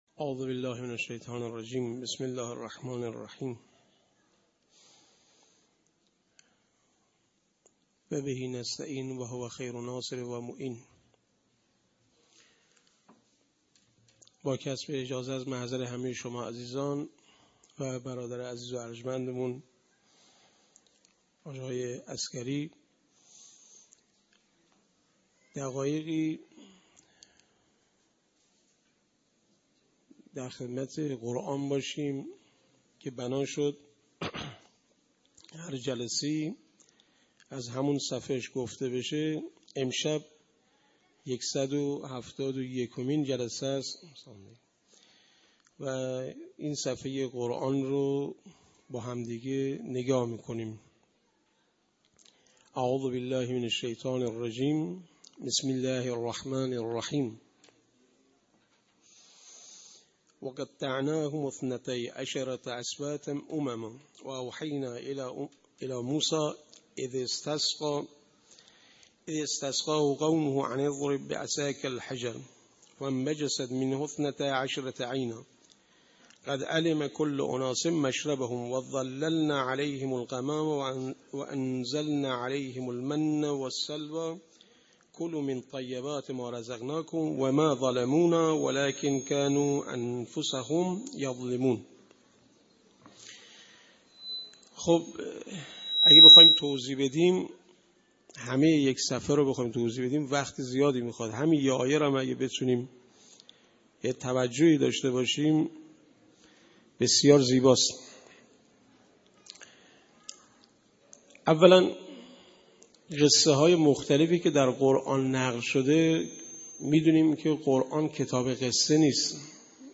کرسی تلاوت و تفسیر قرآن کریم